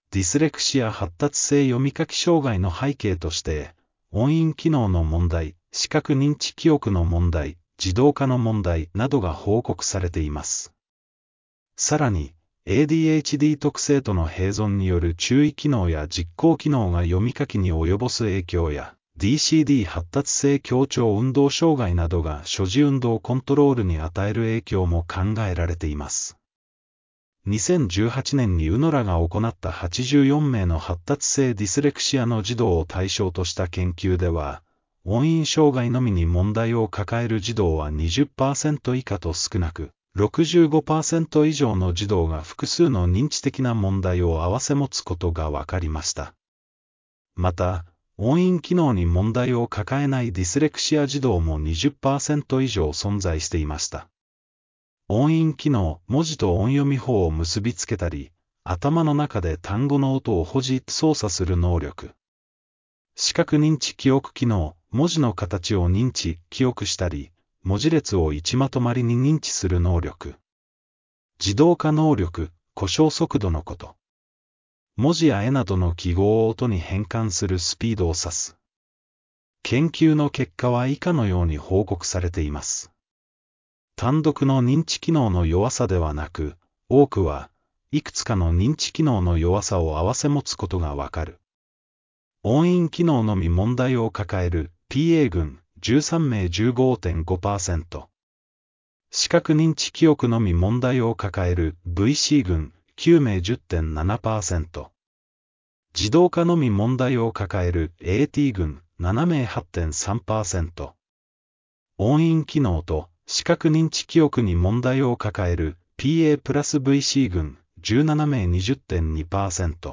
（音声ガイダンス）